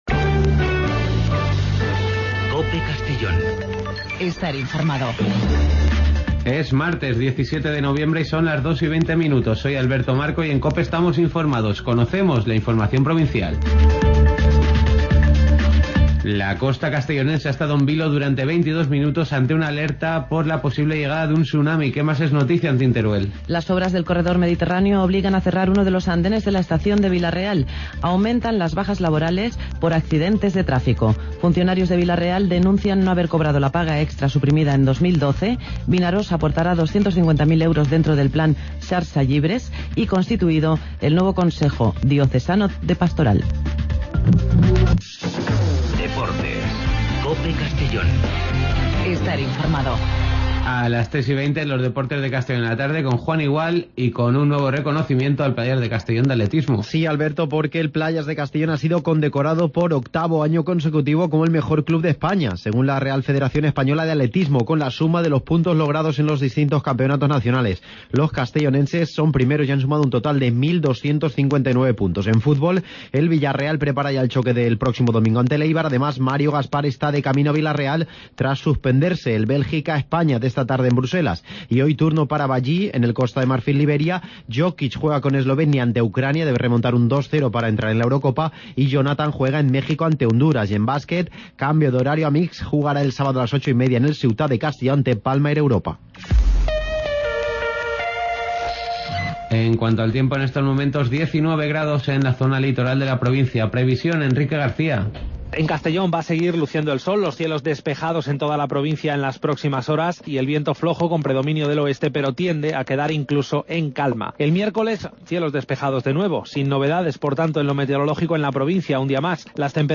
Redacción digital Madrid - Publicado el 17 nov 2015, 17:13 - Actualizado 19 mar 2023, 03:59 1 min lectura Descargar Facebook Twitter Whatsapp Telegram Enviar por email Copiar enlace Espacio informativo a nivel provincial, con los servicios informativos de COPE en la provincia de Castellón.